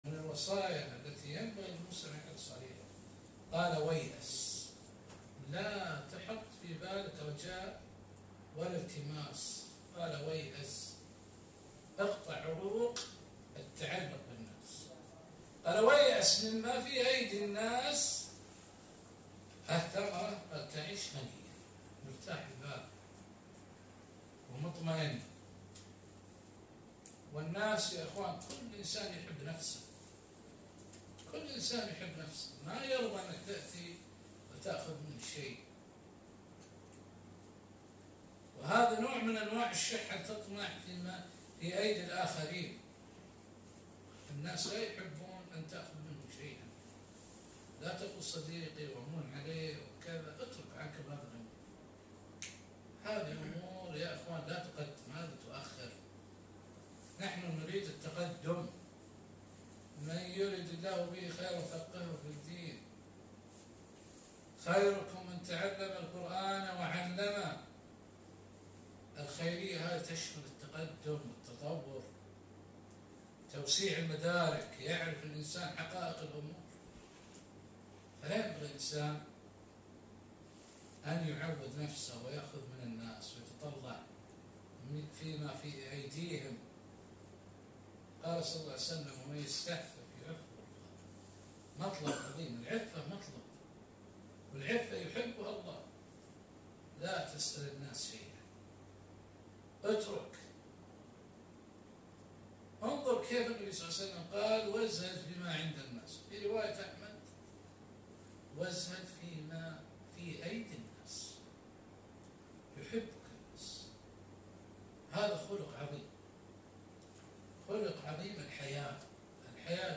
محاضرة - ( وقفات وتأملات مع حديث ازهد في الدنيا )